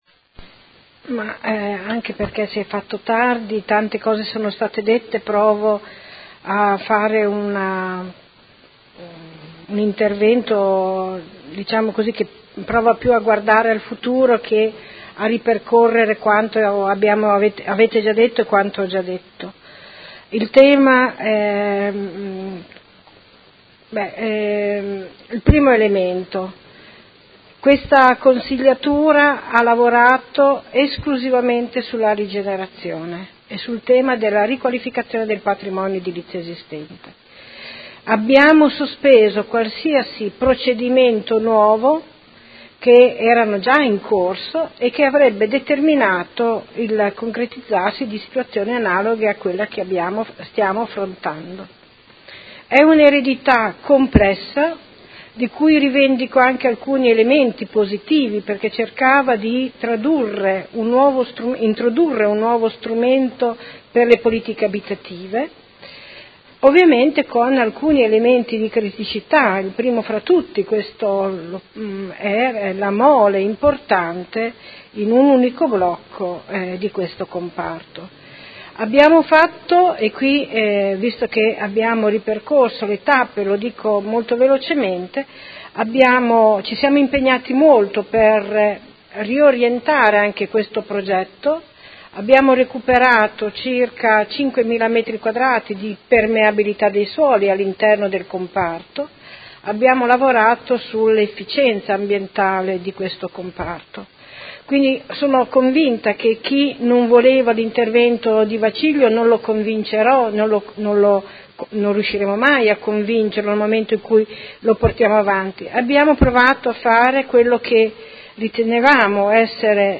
Seduta del 19/07/2018 Dibattito.